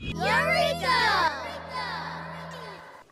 Детский крик «Эврика»